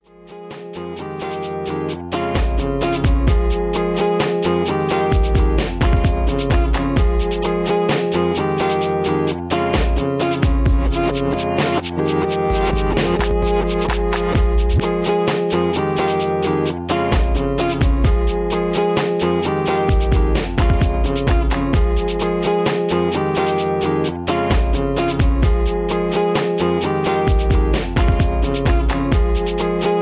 Melodic rap track.